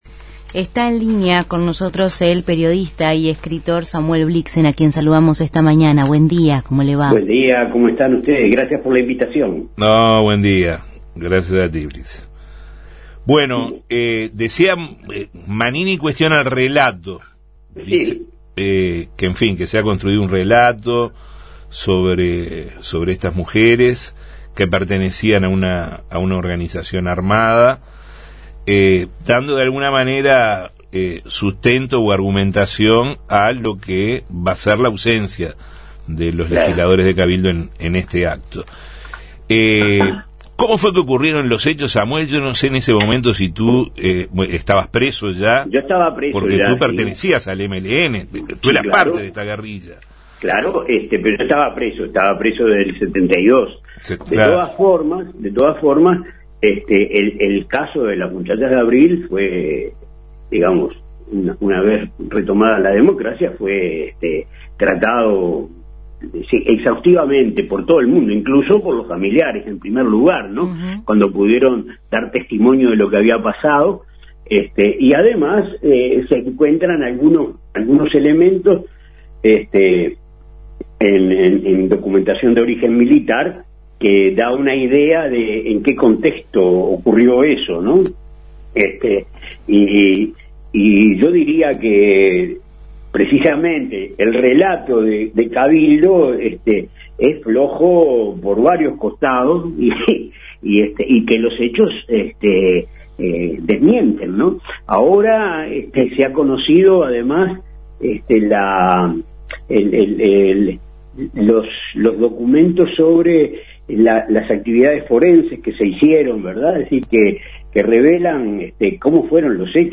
Justos y pecadores conversamos con el periodista y escritor